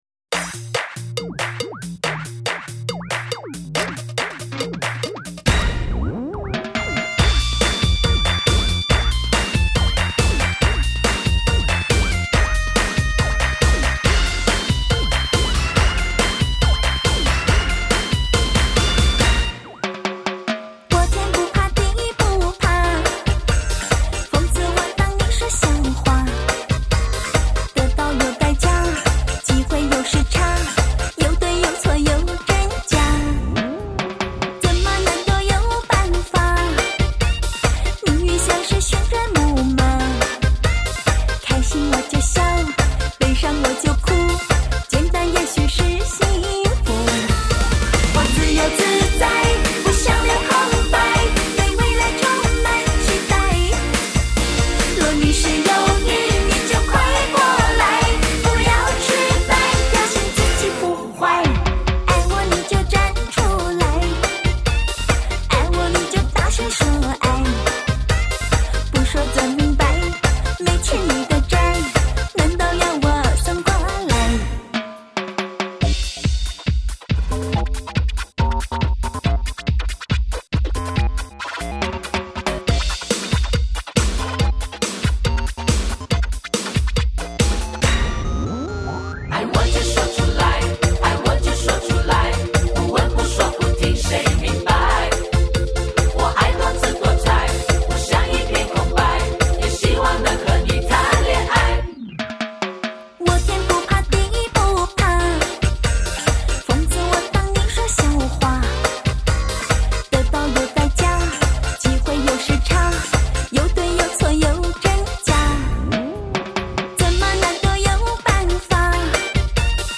演唱的线条幼细，
吐字清晰，句法流畅，几乎听不到换气声。
音抒情，很能表达出歌曲中的缕缕情意，歌声细致深透，荡气回肠。
这张专辑采用24bit/96khz Remastering的运音技术，配合了alloy金